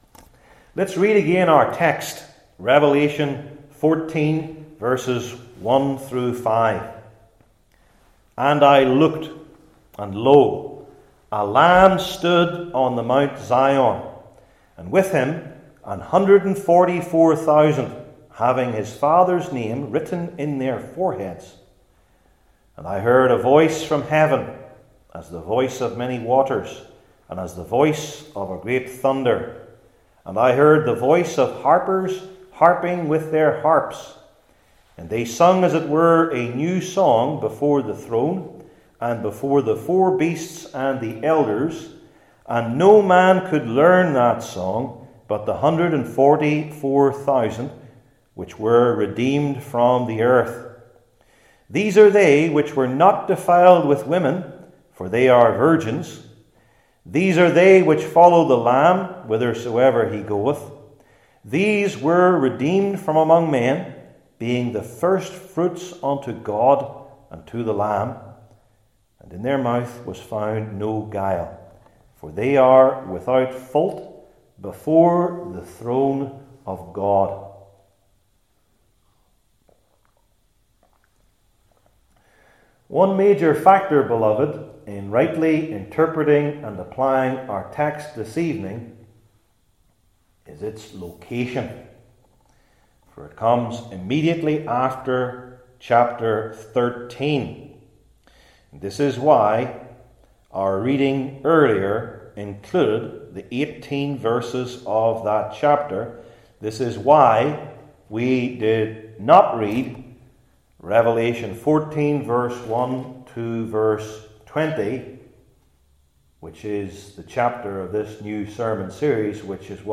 Service Type: New Testament Sermon Series